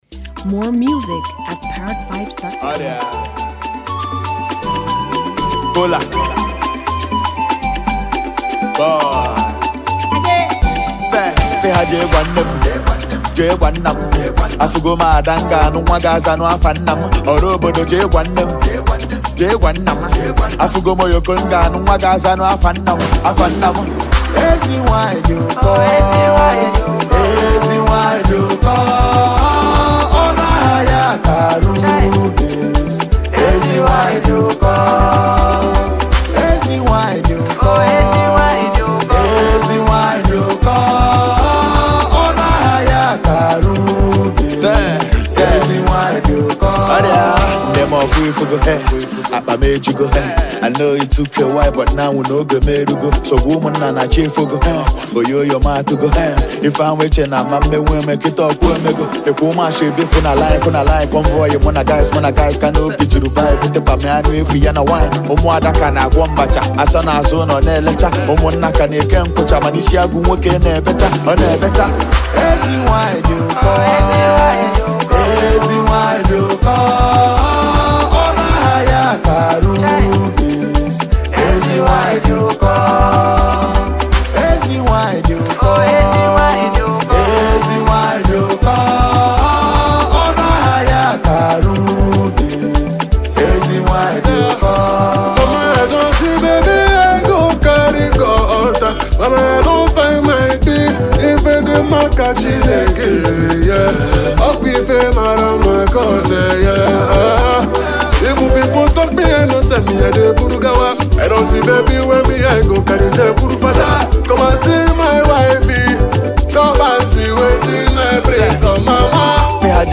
Incredibly talented Nigerian singer and songwriter
melodious single